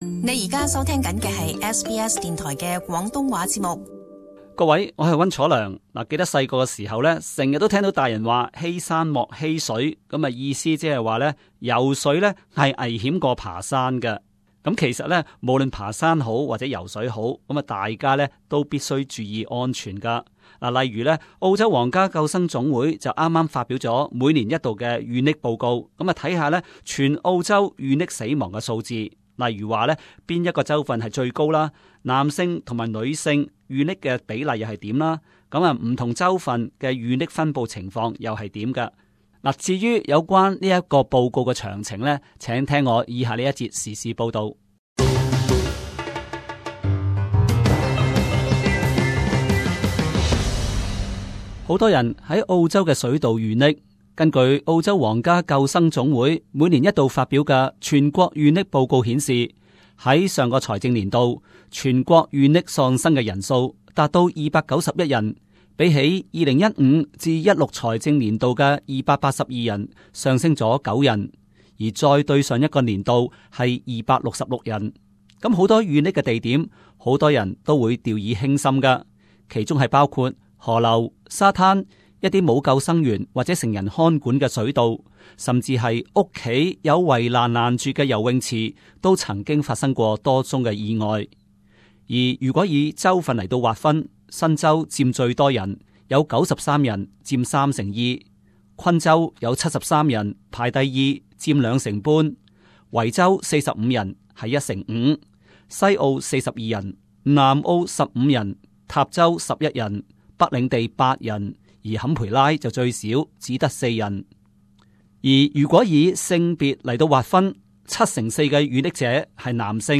【時事報導】 澳洲水道溺斃有上升趨勢